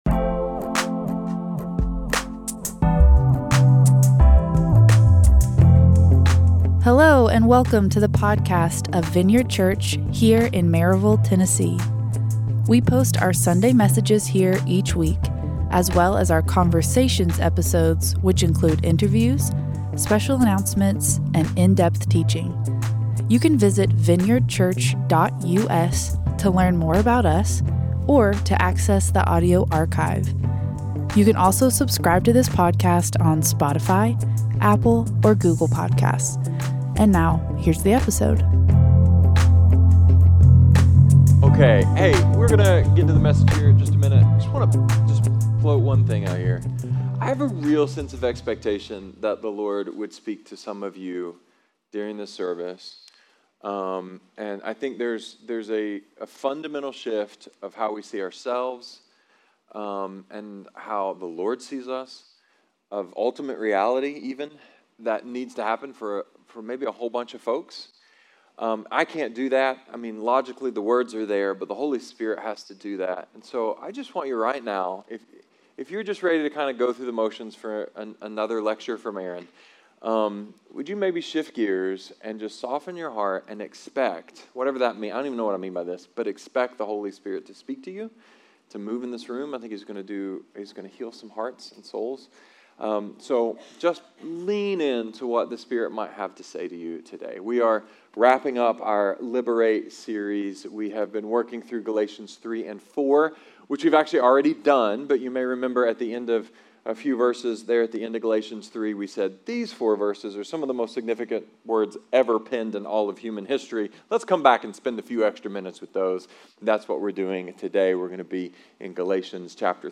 A sermon about status / power / influence… and how to use it.